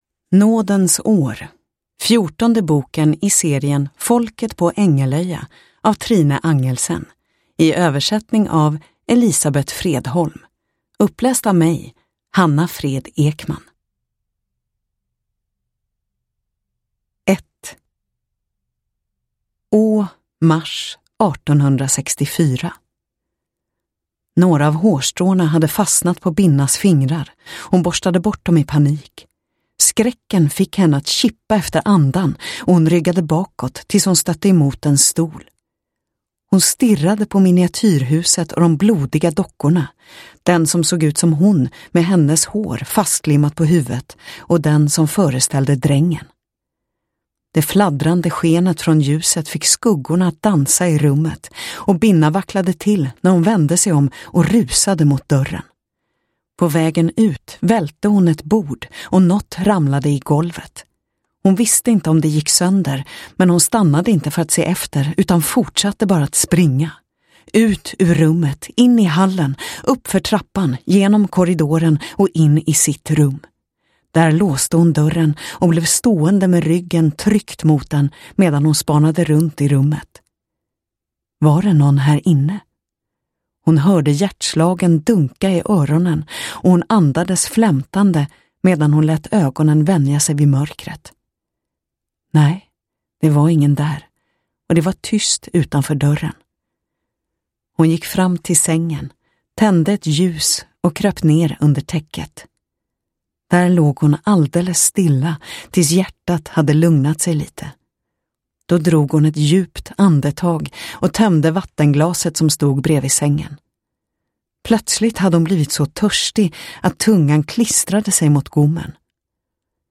Nådens år – Ljudbok – Laddas ner